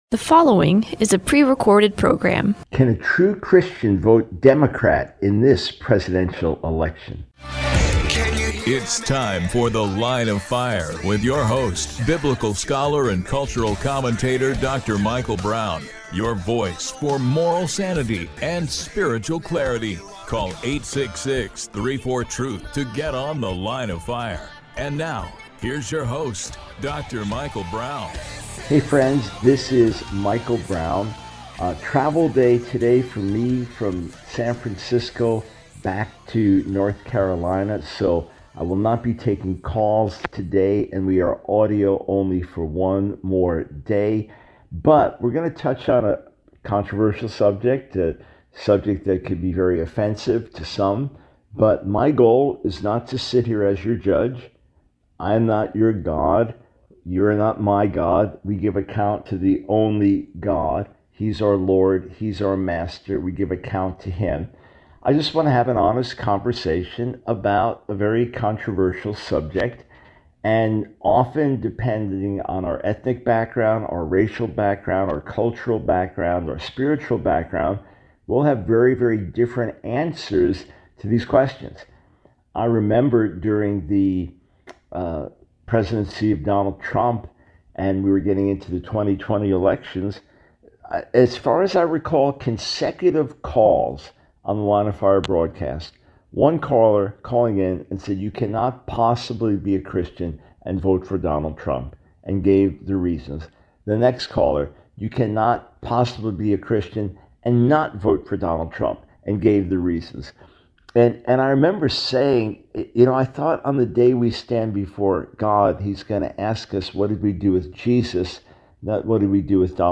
The Line of Fire Radio Broadcast for 08/19/24.